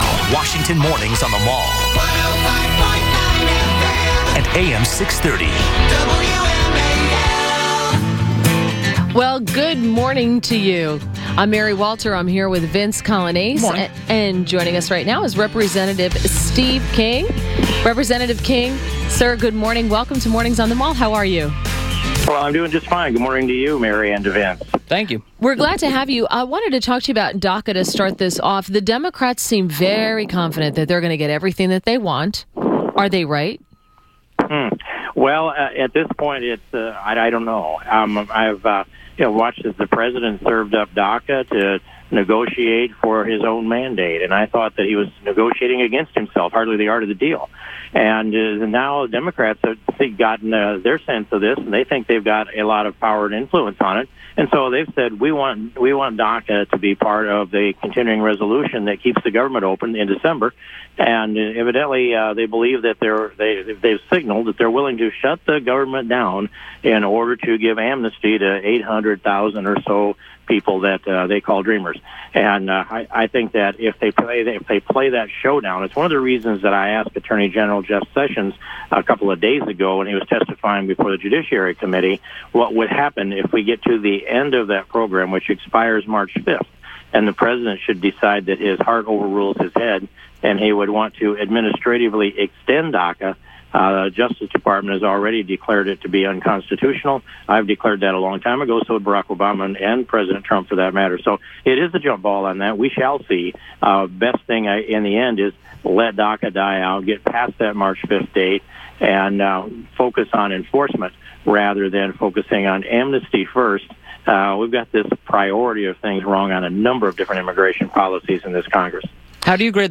WMAL Interview - REP. STEVE KING - 11.16.17